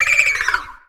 Catégorie:Cri Pokémon (Soleil et Lune) Catégorie:Cri de Froussardine
Cri_0746_Solitaire_SL.ogg